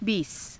ふつう